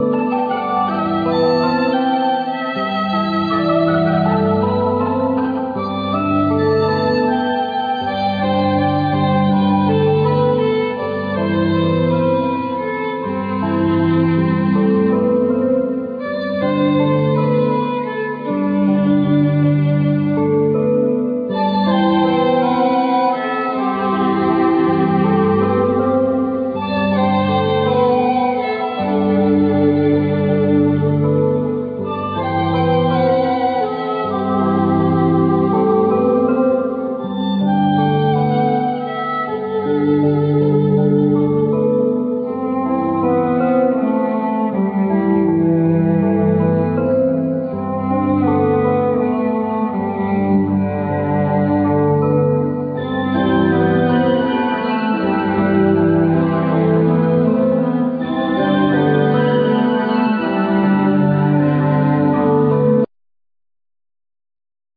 Violin
Viola
Cello
Trumpet
Sax
Clarinet
Piano
Flute
Guitar
Computers,Synthsizers